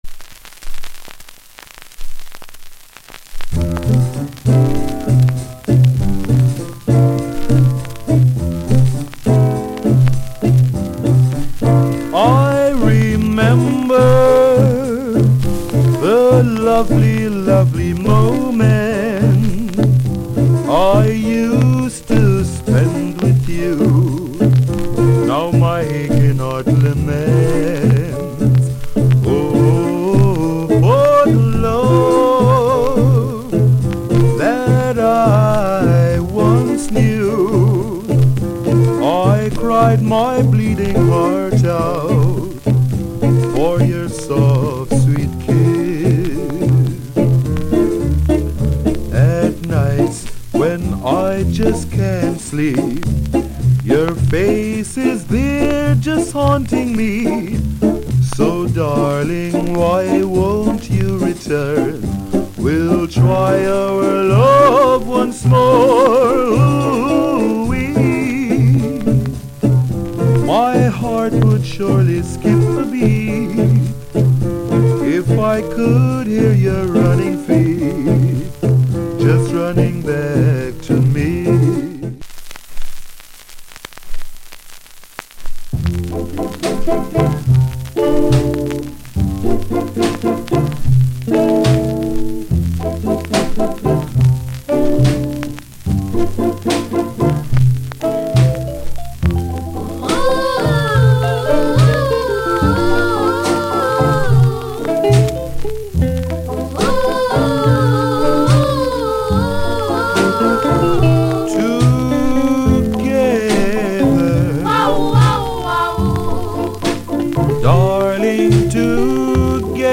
Ballad ** hissy